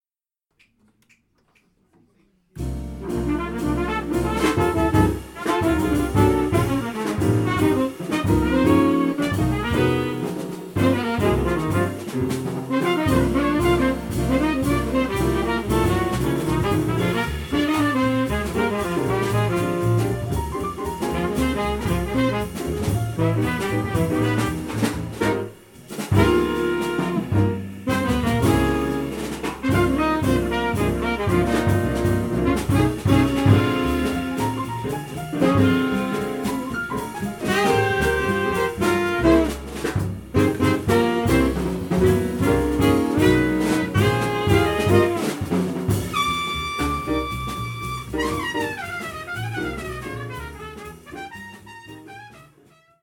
trumpet
sax
piano
bass
drums